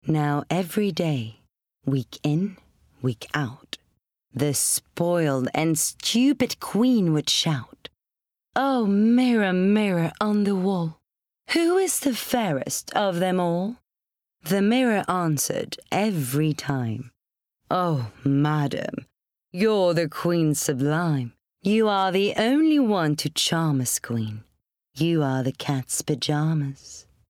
20/30's French/Italian, Authentic/Sultry/Husky
Boursin – English/French Accent YSL – English/French Accent Lavazza – English/Italian Accent LiuJo – English/Italian Accent